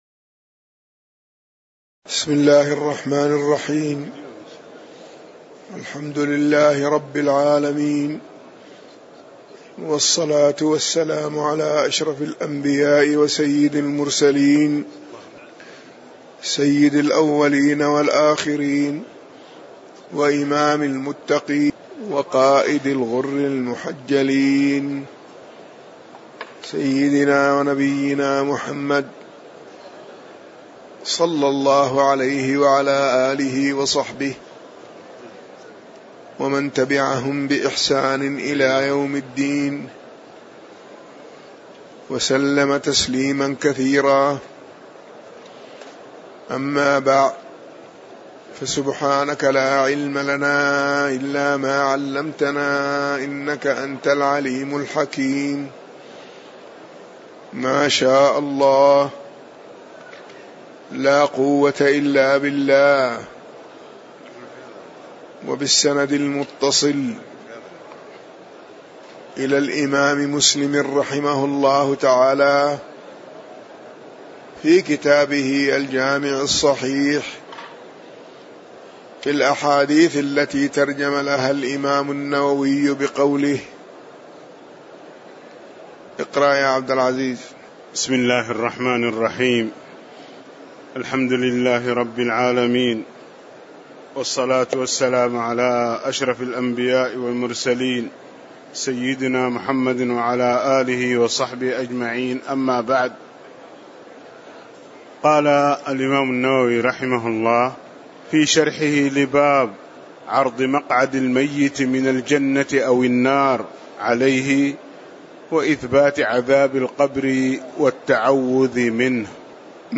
تاريخ النشر ١٢ رجب ١٤٣٨ هـ المكان: المسجد النبوي الشيخ